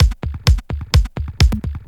Electrohouse Loop 128 BPM (26).wav